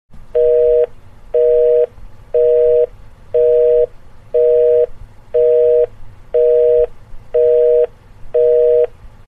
Другие рингтоны по запросу: | Теги: Занято, гудки